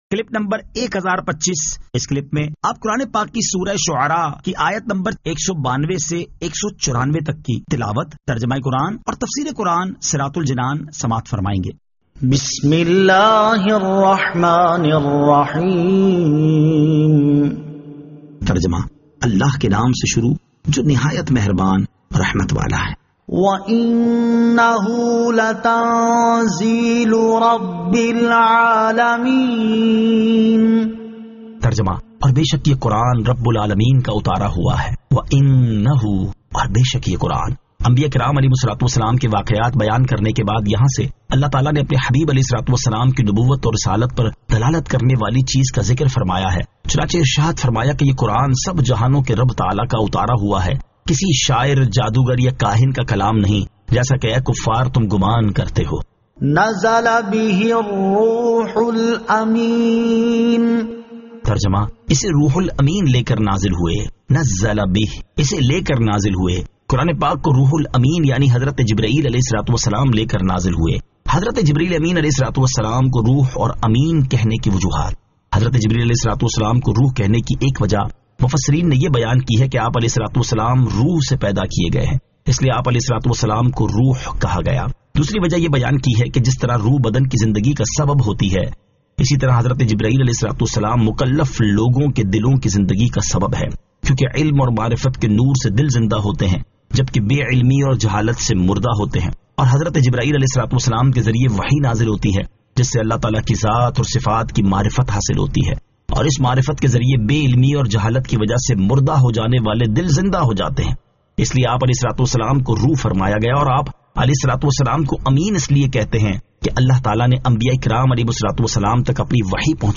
Surah Ash-Shu'ara 192 To 194 Tilawat , Tarjama , Tafseer